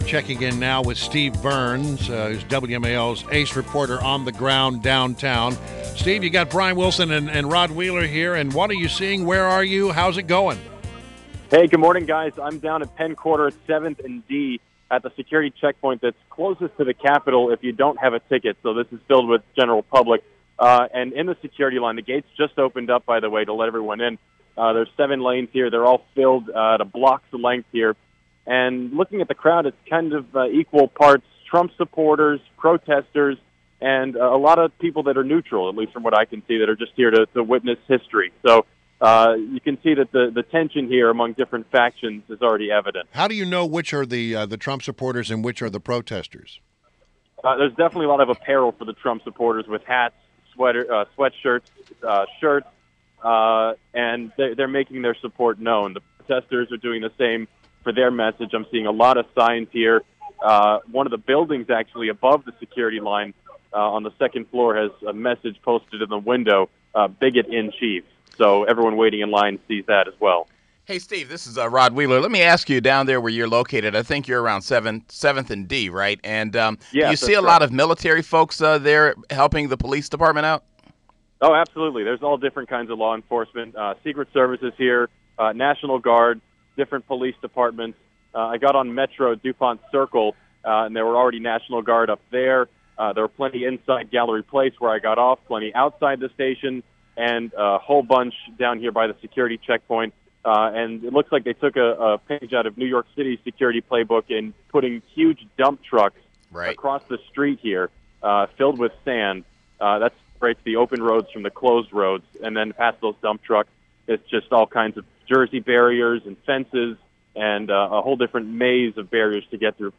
WMAL Interview
INTERVIEW